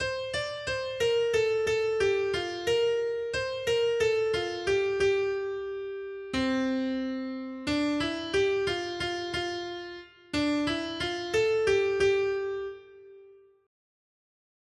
Noty Štítky, zpěvníky ol542.pdf responsoriální žalm Žaltář (Olejník) 542 Skrýt akordy R: Ze záhuby vysvobodíš můj život, Hospodine! 1.